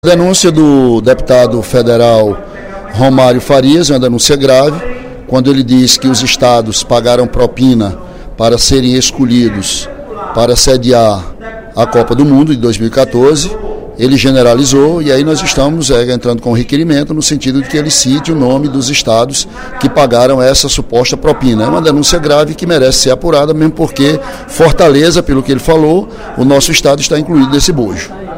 “Queremos saber se o Ceará pagou”, afirmou Ely em pronunciamento nesta quarta-feira (12/12).